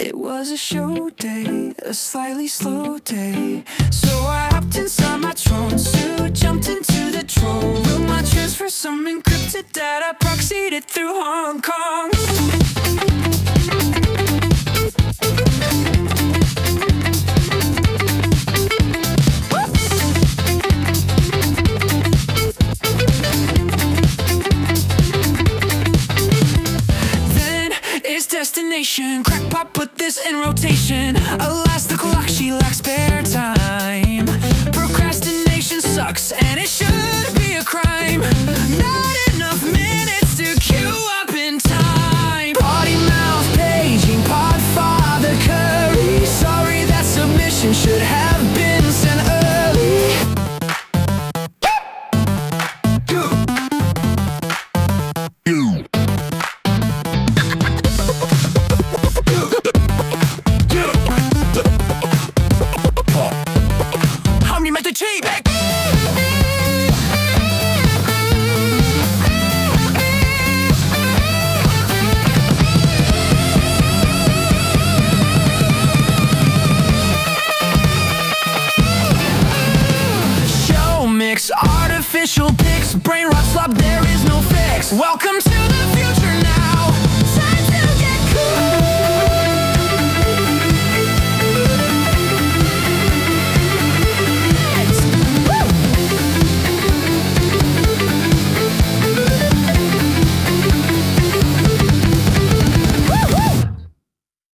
End of Show Mixes: